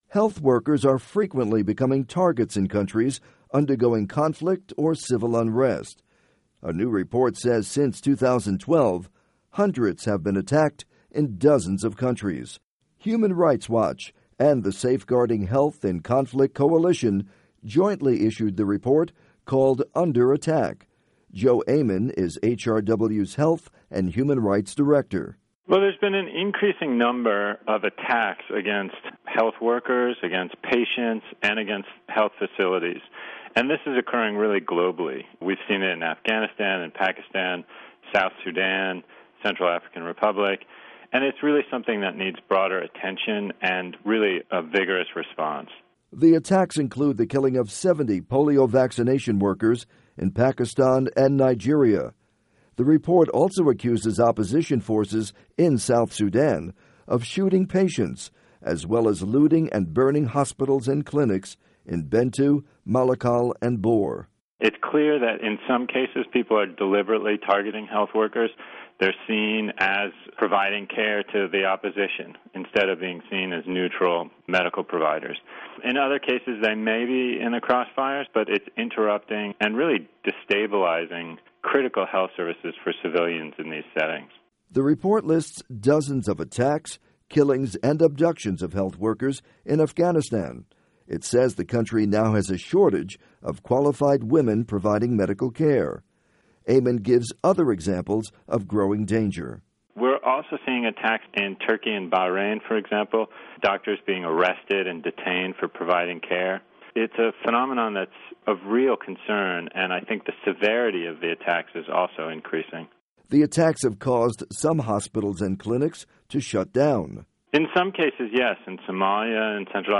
report on attacks on health workers